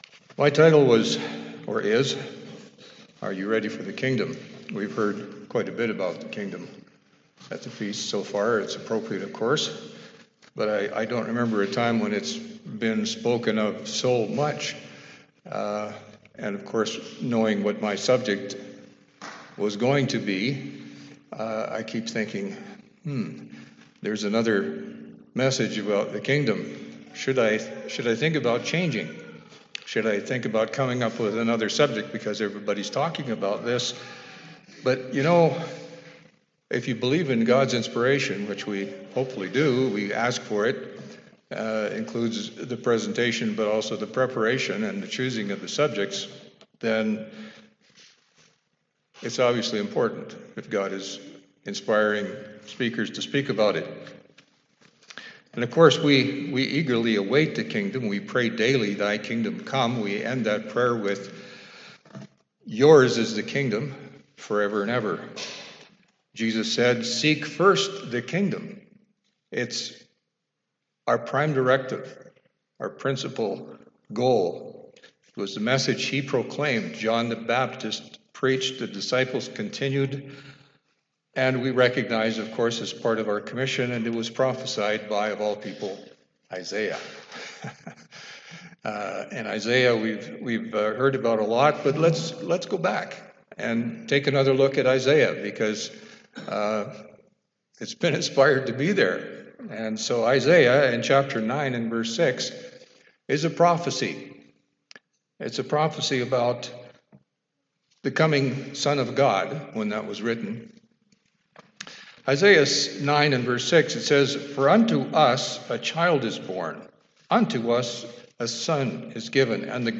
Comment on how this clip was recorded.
This sermon was given at the Cochrane, Alberta 2020 Feast site.